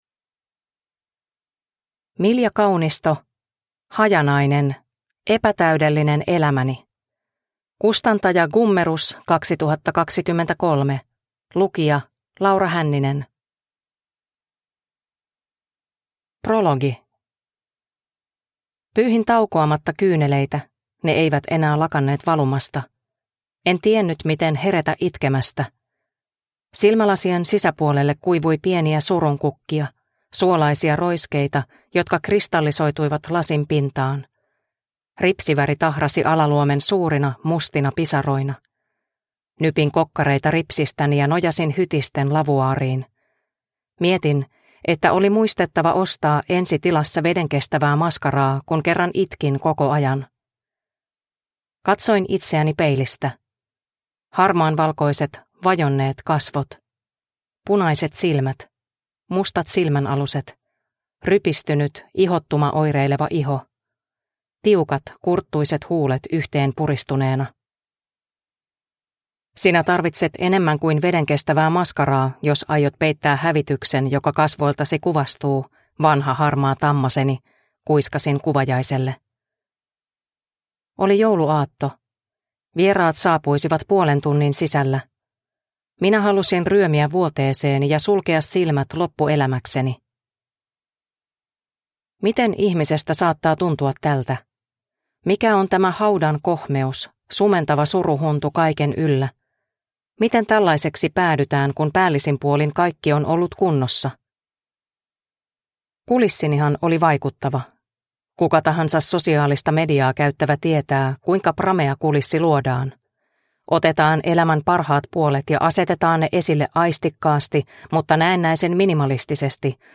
Hajanainen – Ljudbok – Laddas ner